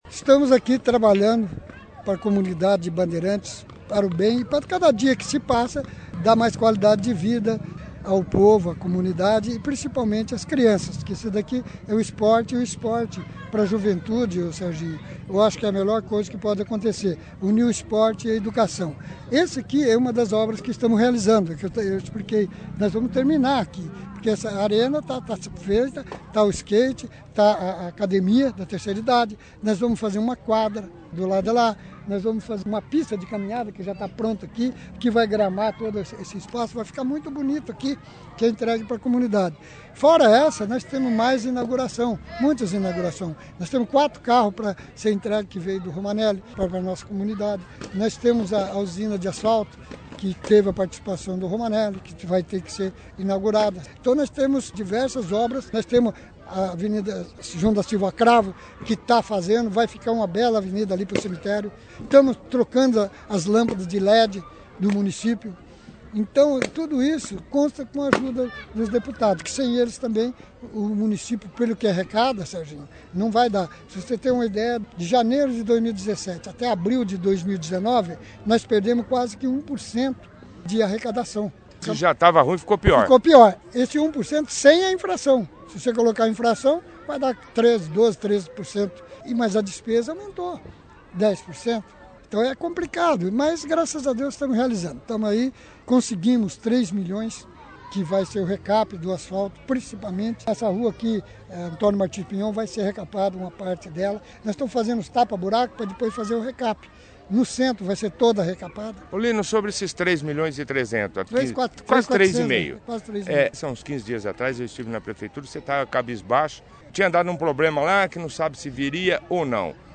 A inauguração foi destaque da edição deste sábado, 29/06, do jornal Operação Cidade com a participação do prefeito Lino e do deputado Romanelli, que falaram sobre a arena e também sobre uma verba de um convênio assinado com o governo do estado de mais de 3 milhões de reais para pavimentação asfáltica assinado esta semana.